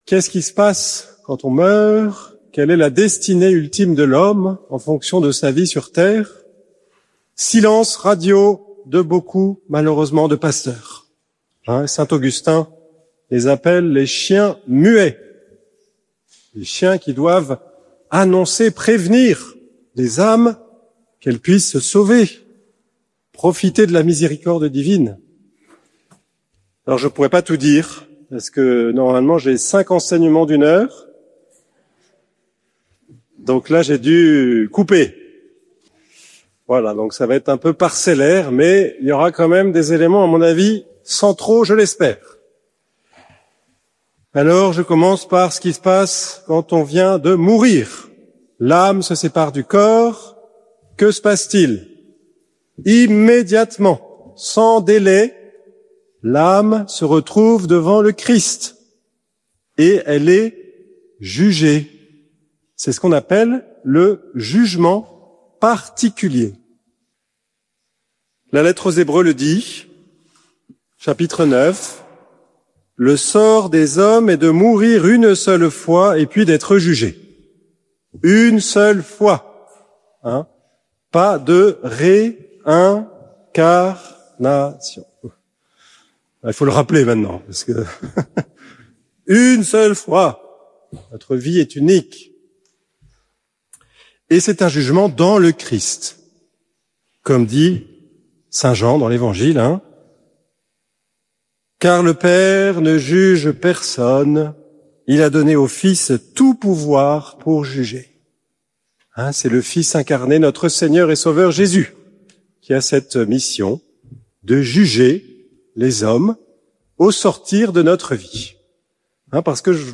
ND Laus, Congrès Adoratio